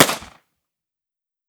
38 SPL Revolver - Gunshot B 005.wav